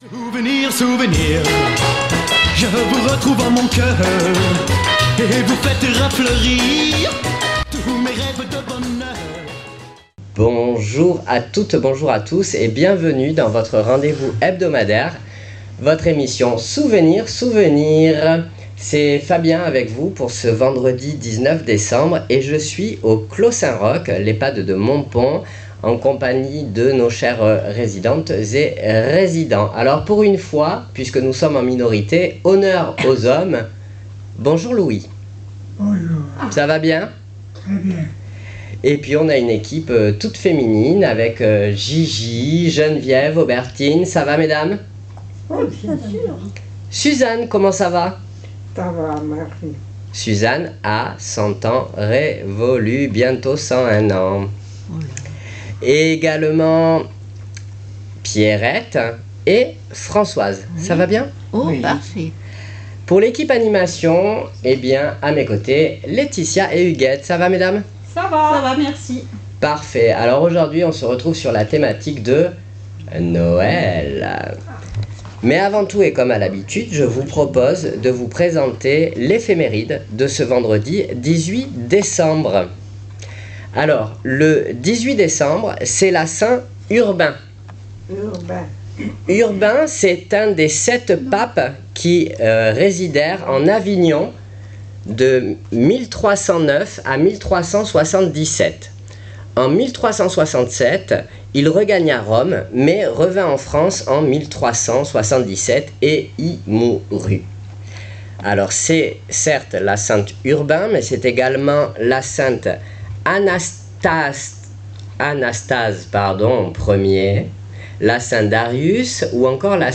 Souvenirs Souvenirs 19.12.25 à l'Ehpad de Montpon " Noel "